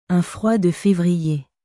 Un froid de févrierアン フォワ ドゥ フェヴリエ